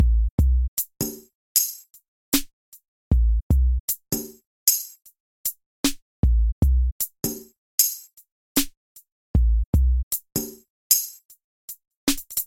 小鼓 " 小嗨小鼓
描述：圈套，鼓套装tama dw ludwig打击乐打击样本鼓
Tag: 路德维希 圈套 DW 撞击声 样品 试剂盒 多摩 打击